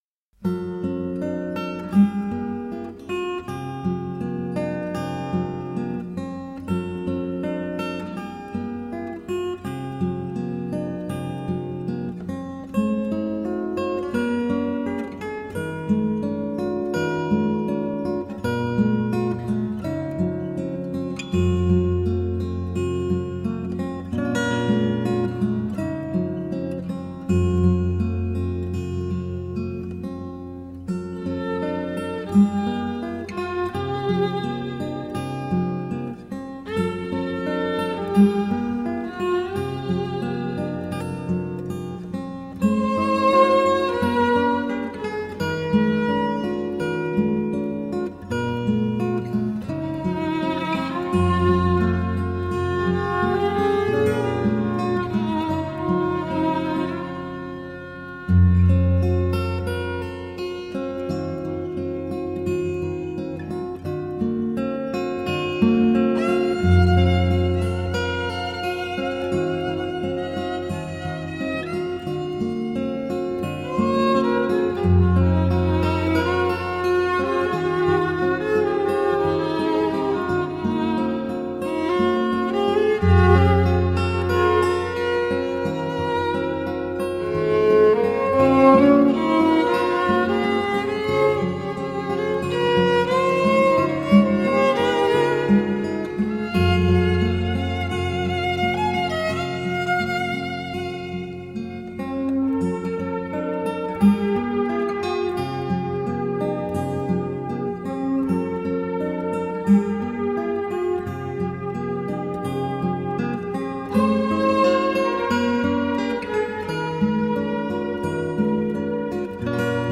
Improvised acoustic guitar with textures of chiming chords.
It is played by real people, not computers.